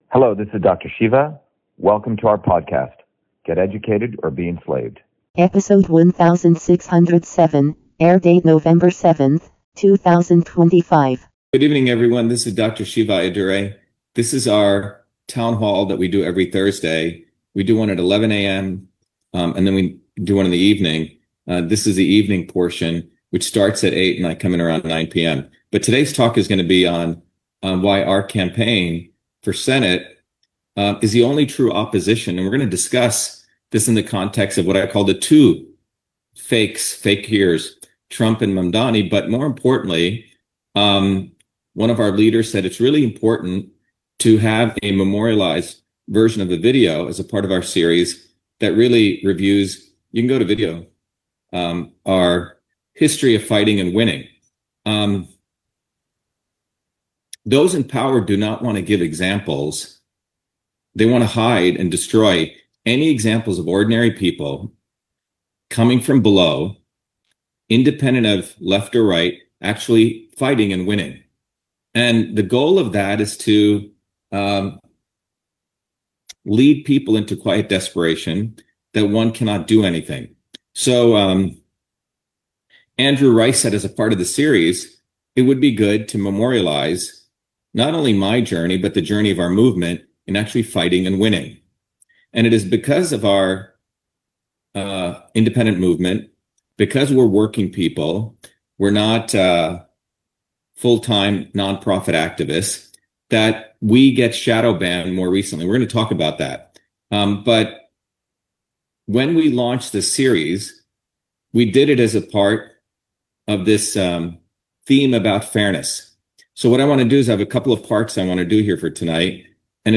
In this interview, Dr.SHIVA Ayyadurai, MIT PhD, Inventor of Email, Scientist, Engineer and Candidate for President, Talks about Shiva4Senate 2026: Our History of Fighting and Winning. The Only True Opposition Beyond The Two Fakirs Trump and Mamdani